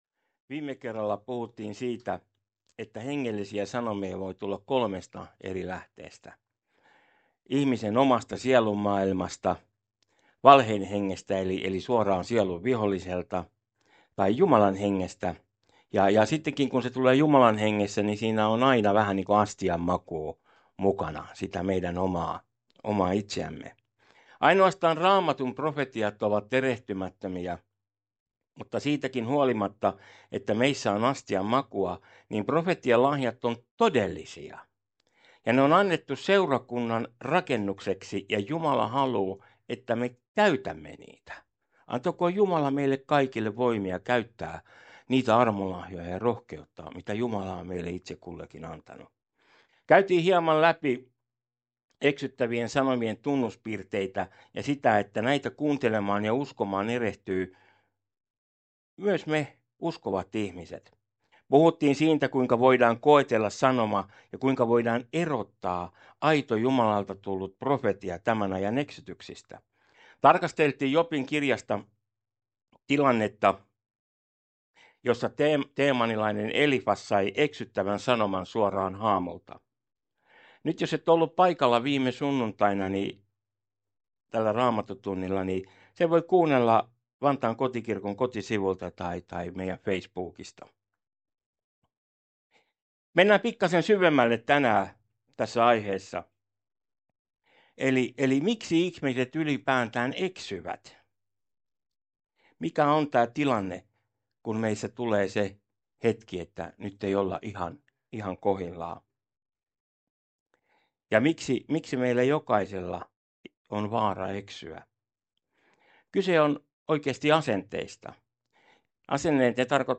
Vantaan Kotikirkko - Kuuntele puheita netissä
Service Type: Raamattutunti